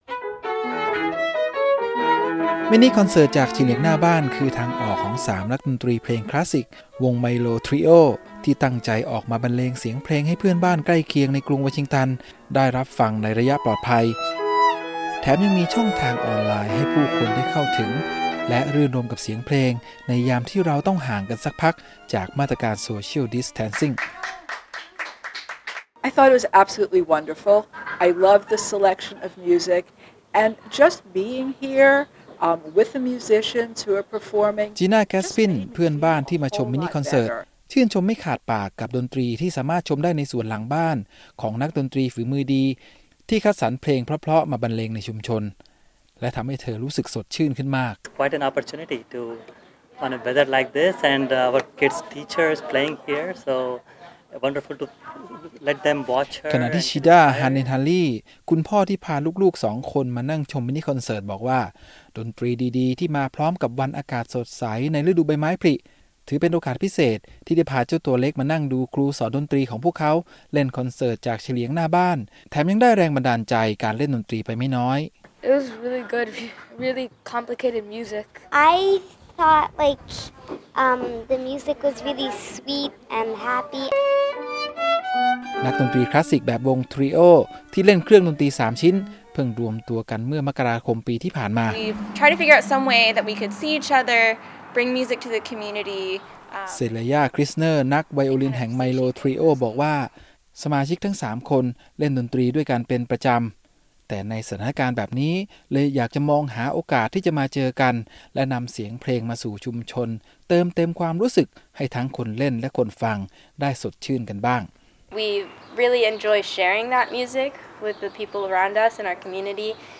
นักดนตรีผู้รักในเสียงเพลงชาวอเมริกันจัดฟรีคอนเสริ์ตเล่นดนตรีจากบนระเบียงบ้านSocially Distant Concert ใจกลางย่านที่พักอาศัยในกรุงวอชิงตันเพื่อสร้างบรรยากาศดีๆแบ่งปันให้คนในชุมชน และส่งให้กำลังใจผู้คนผ่านสื่อออนไลน์ ท่ามกลางข้อจำกัดในช่วงการประกาศคำสั่งห้ามออกเคหะสถานในภาวะการระบาดของเชื้อโคโรนาไวรัส